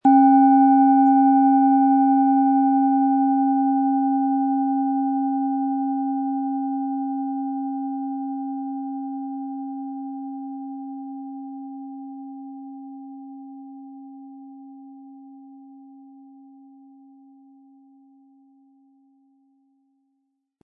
Wie klingt diese tibetische Klangschale mit dem Planetenton Biorhythmus Körper?
Um den Original-Klang genau dieser Schale zu hören, lassen Sie bitte den hinterlegten Sound abspielen.
Durch die traditionsreiche Herstellung hat die Schale stattdessen diesen einmaligen Ton und das besondere, bewegende Schwingen der traditionellen Handarbeit.
PlanetentonBiorythmus Körper
MaterialBronze